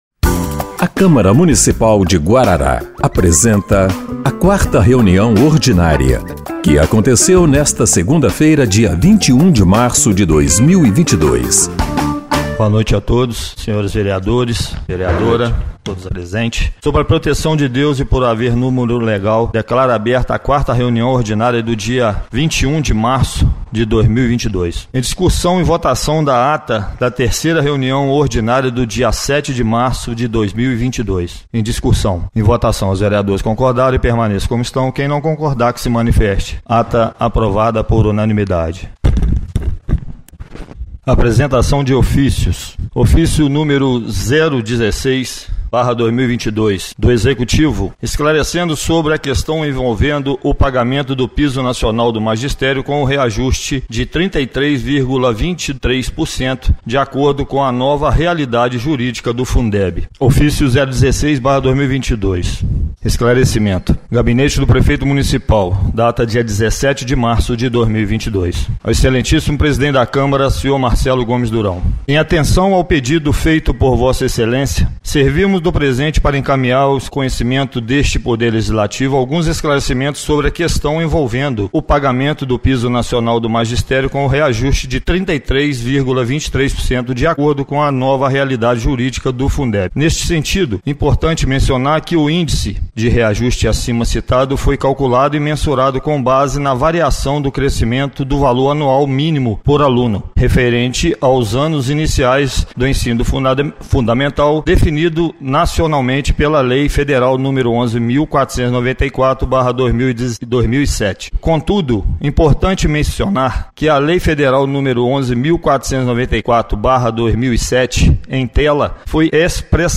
4ª Reunião Ordinária de 21/03/2022